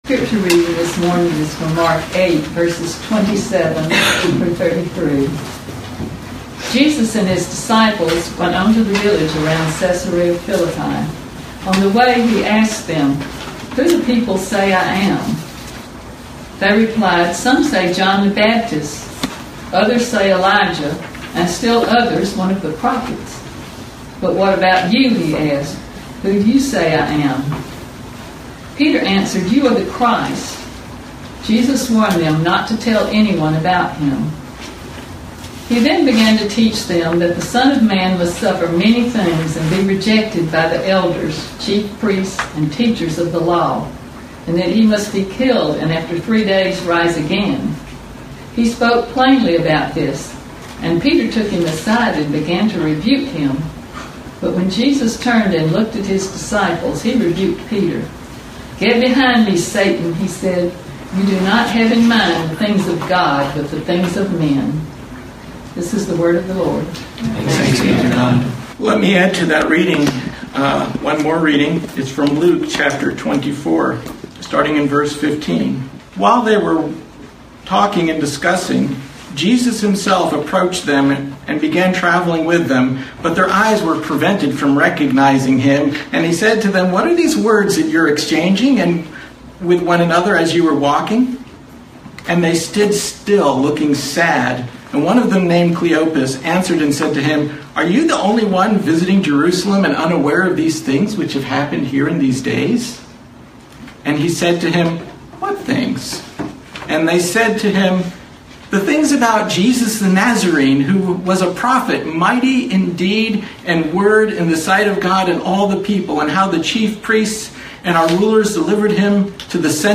Passage: Mark 8:27-33 Service Type: Sunday Morning